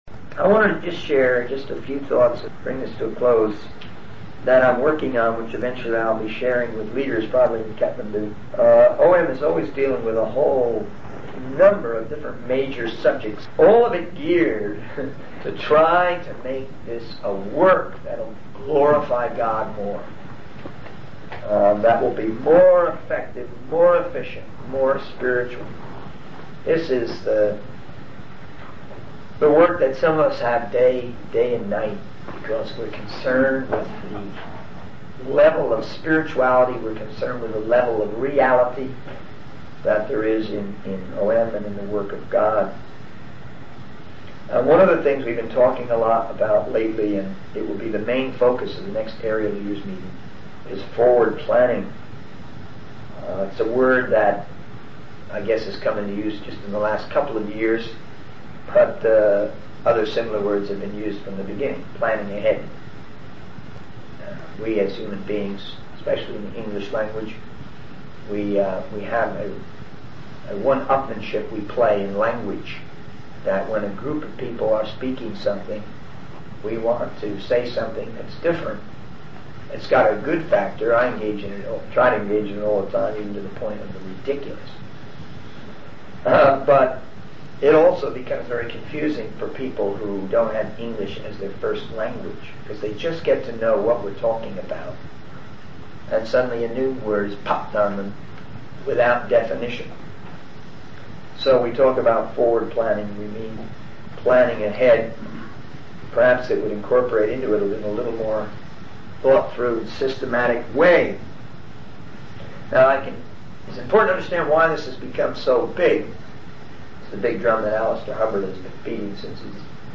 In this sermon, the speaker emphasizes the importance of being involved in world evangelism. He compares the task of spreading the word of God to fishing, highlighting the joy and thrill of seeing someone come to Christ.